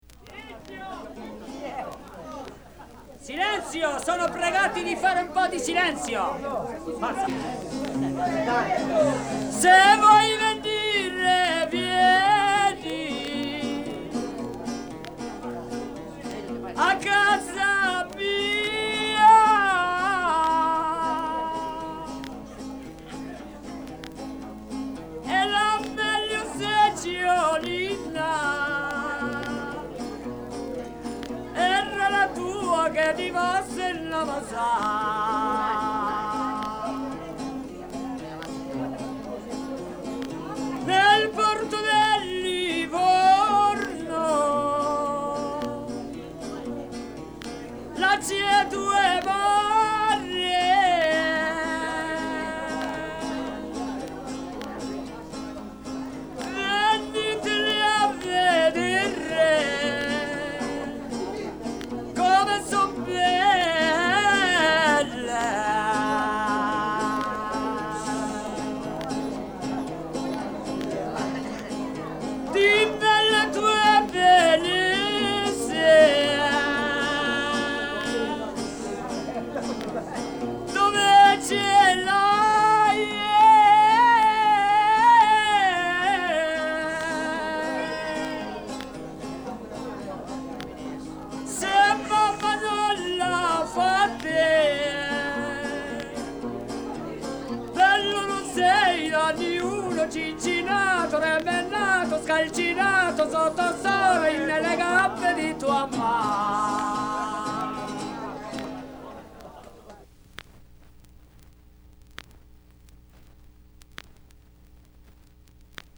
NORD E CENTRO ITALIA - DALLE RICERCHE DI ALAN LOMAX )1954)
21-stornello.mp3